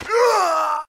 PlayerKilled.ogg